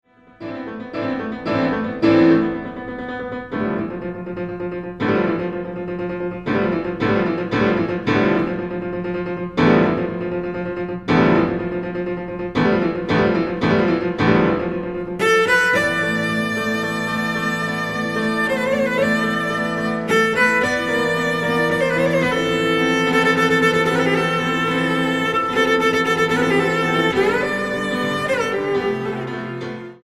violoncello
piano